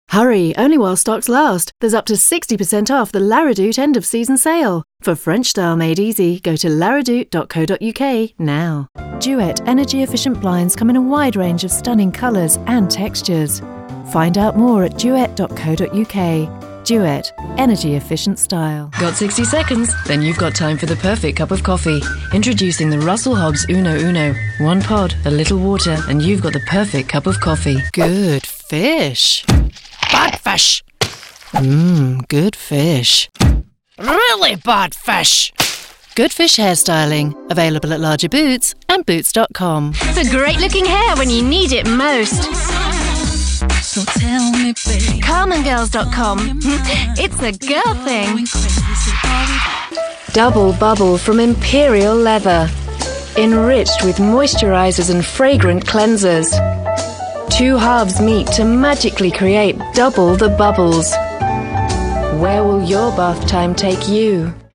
Clear, warm, professional, versatile.